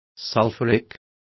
Complete with pronunciation of the translation of sulphuric.